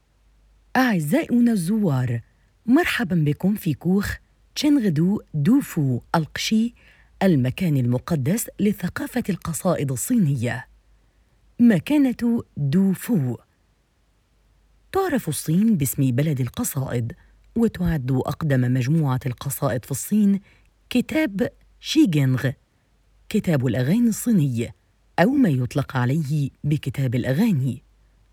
阿拉伯语样音试听下载
阿拉伯语配音员（女1） 阿拉伯语配音员（女2）